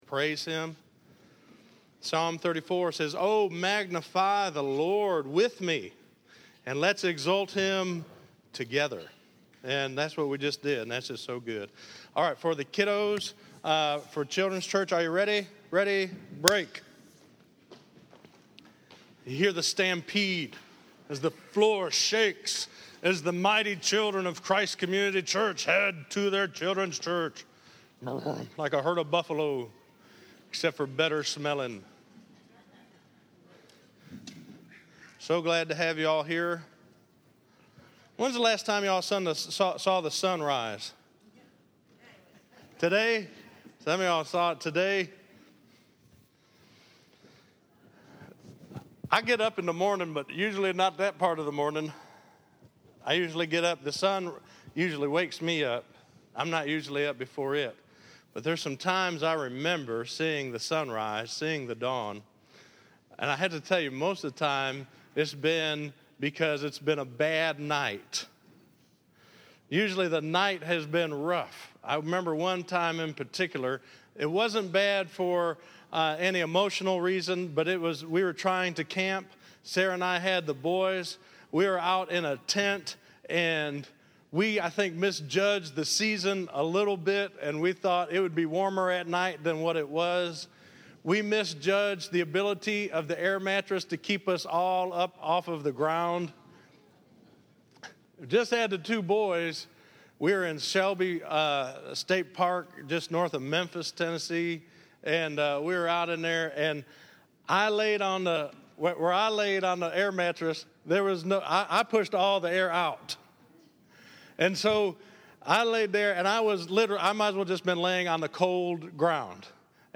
Listen to The Word Brings the SON Rise - 01_18_15_Sermon.mp3